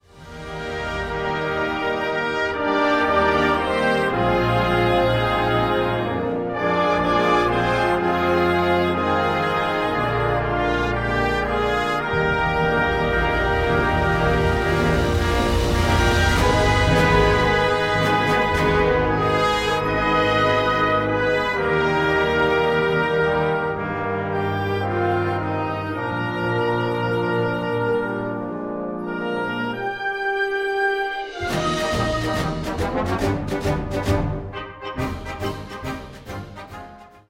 Besetzung: Blasorchester
Capriciously entertaining!